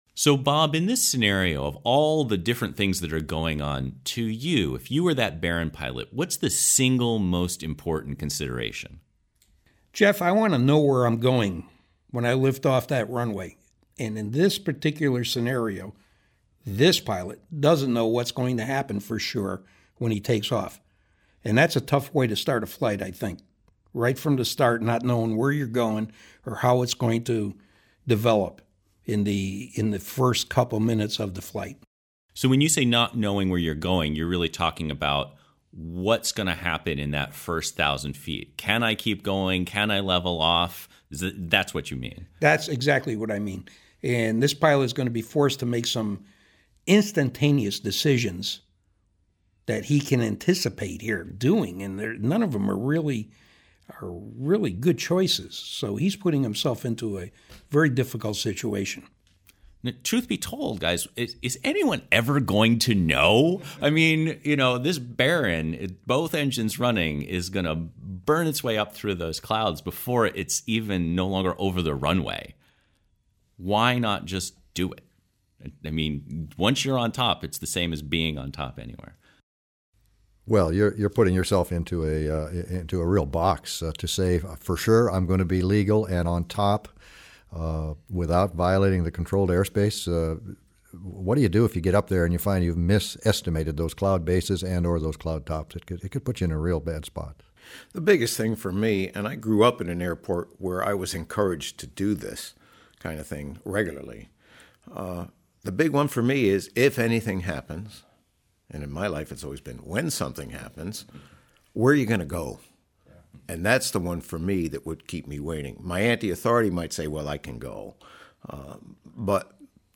Murphys law of Departures_rountable.mp3